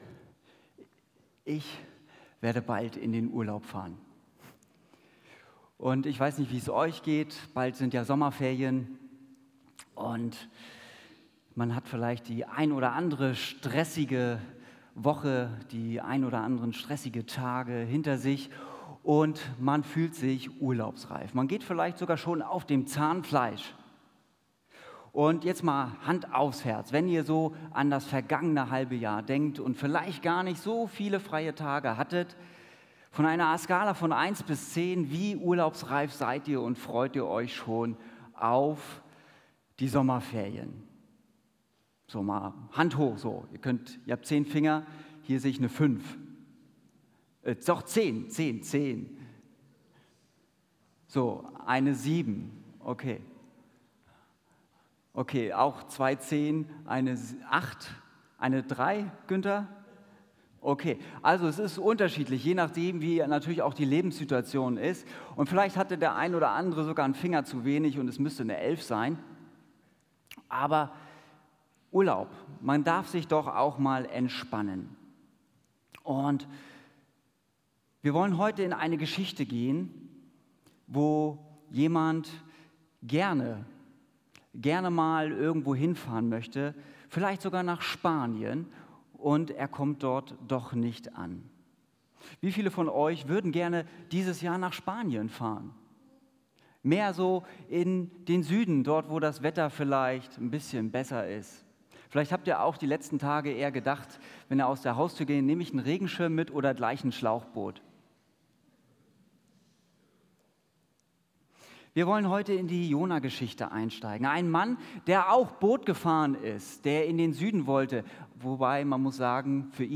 Predigt Jona